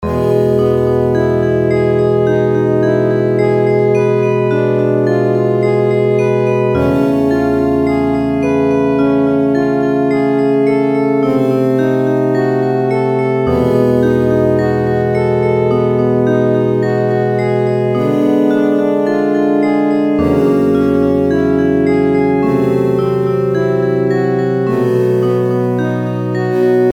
以前SH-51で作成したmmfファイルをmp3ファイルに録音し直しました。
再生する機種により、音は、多少異なって聞こえます。